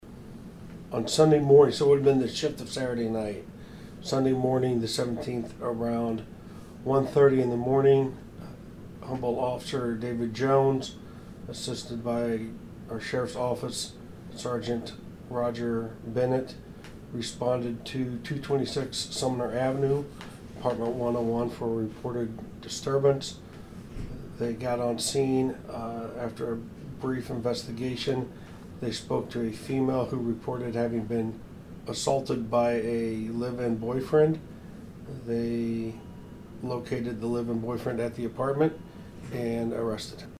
Police Chief Joel Sanders provided the details on the incident and arrest.